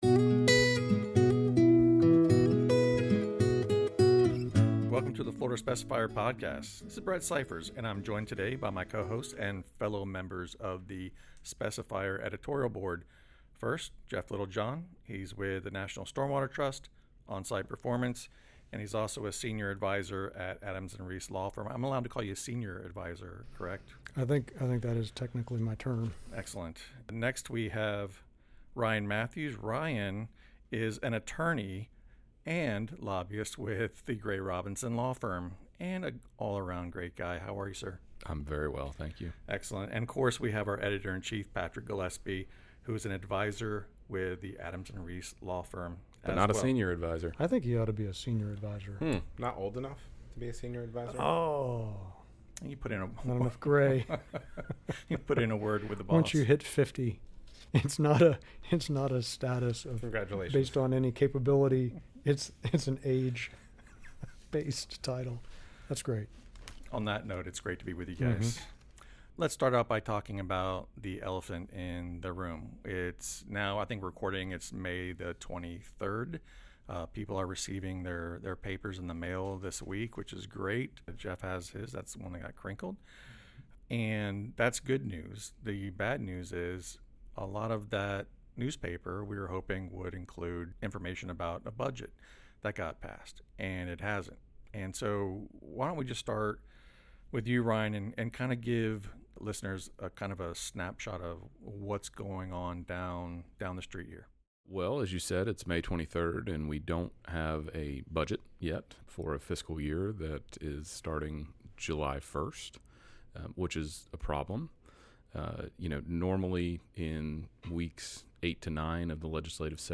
roundtable conversation